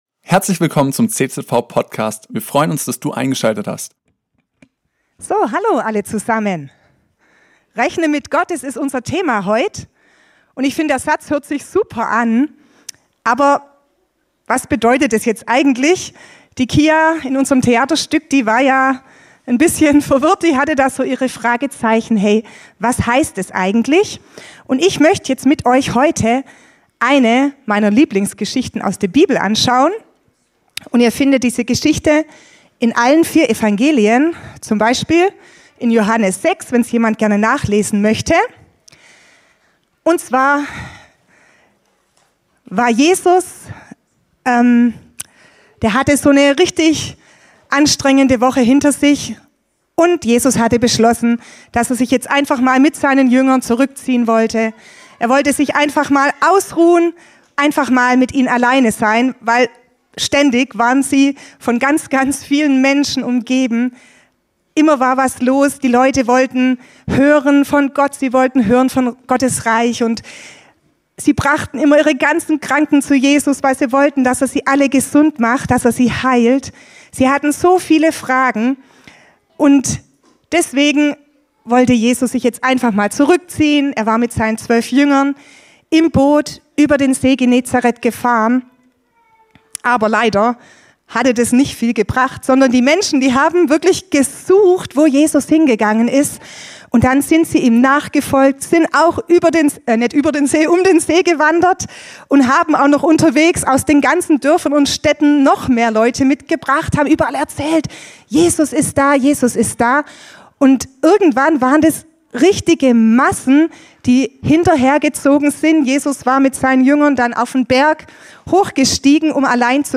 Gottesdient zum Schulstart 2025; Predigttext Joh. 6, 1-15